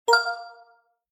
new-notification.mp3